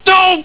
doh.au